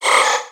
Cri de Tarsal dans Pokémon X et Y.